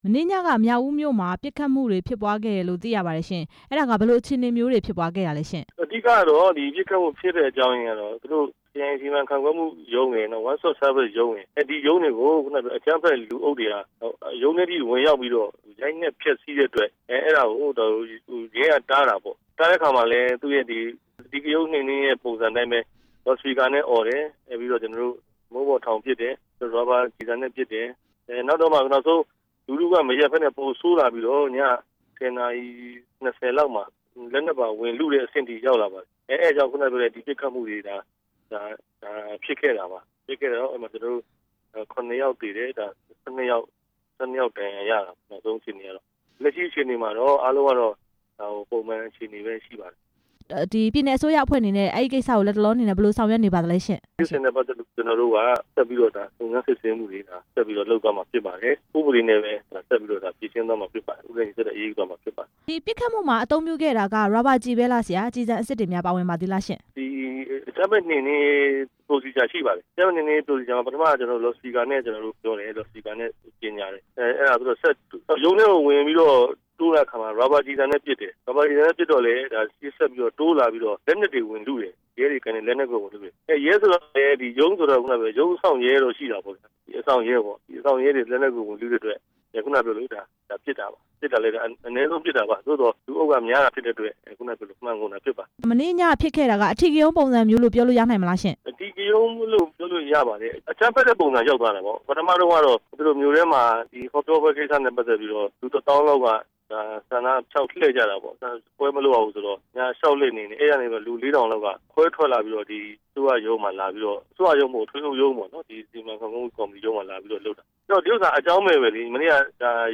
မြောက်ဦးမြို့က ပစ်ခတ်မှုတွေအကြောင်း ဦးတင်မောင်ဆွေနဲ့ ဆက်သွယ်မေးမြန်းချက်